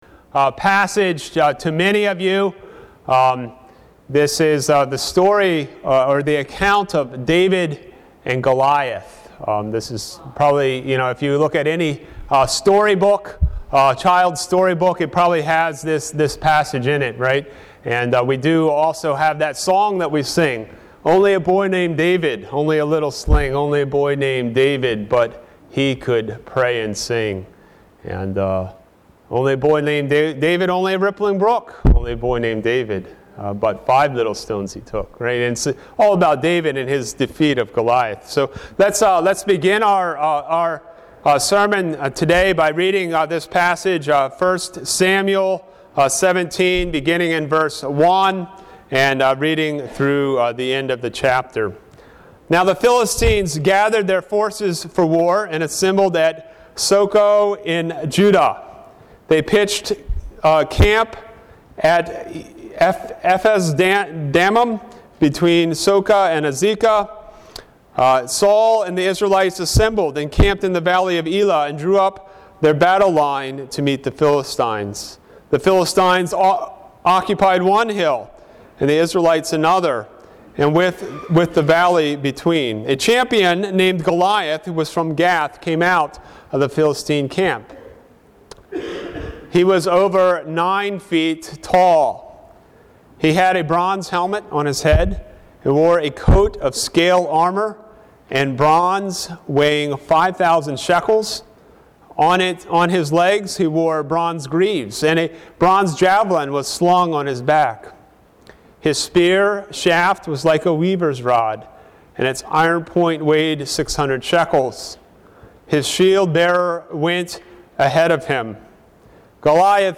Sermon: 1 Samuel 17
Sermon1Samuel17.mp3